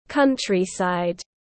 Nông thôn tiếng anh gọi là countryside, phiên âm tiếng anh đọc là /ˈkʌn.tri.saɪd/.
Countryside /ˈkʌn.tri.saɪd/